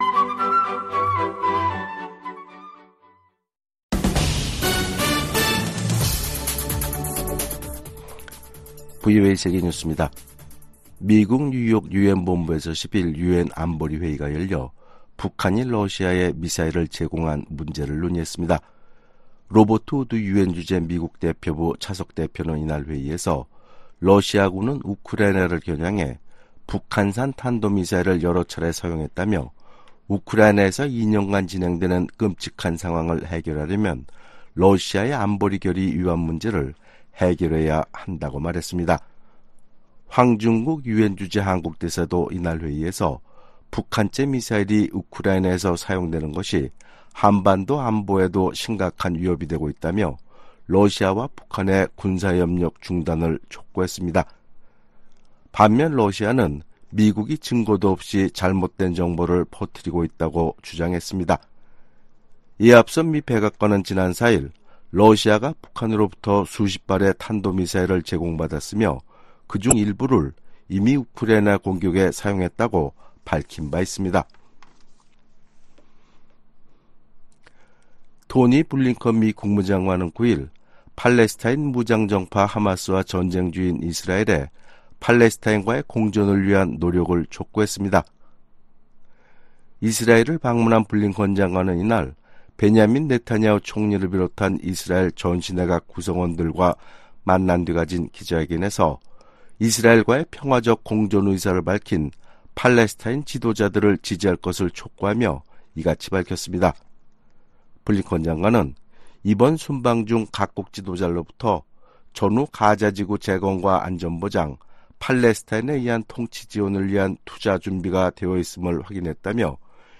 VOA 한국어 아침 뉴스 프로그램 '워싱턴 뉴스 광장' 2024년 1월 11일 방송입니다. 러시아가 또다시 우크라이나 공격에 북한산 탄도미사일을 사용했다고 백악관이 밝혔습니다. 미국과 한국,일본 등 49개국 외교장관이 공동성명을 내고 러시아의 북한 탄도미사일 사용을 강력 규탄했습니다. 김정은 북한 국무위원장은 한국을 주적으로 규정하면서 무력으로 자신들을 위협하면 초토화하겠다고 위협했습니다.